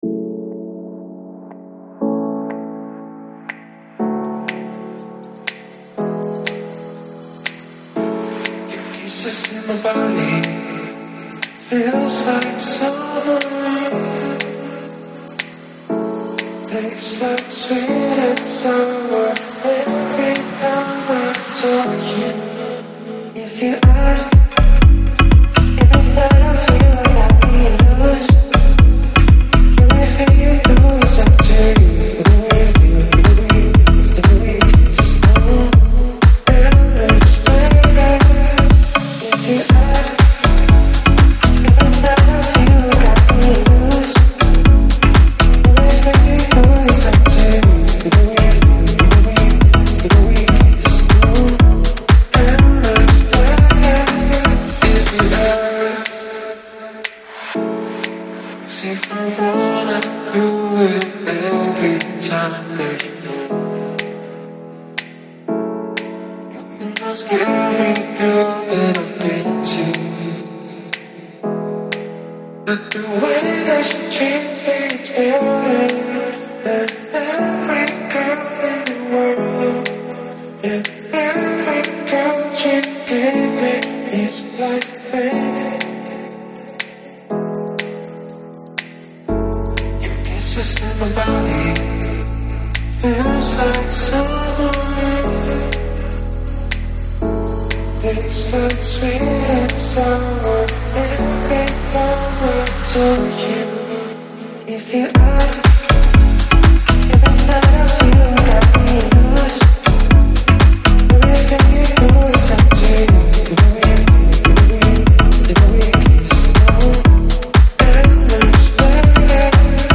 Жанр: Lounge, Chill House, Downtempo, Pop, Electronic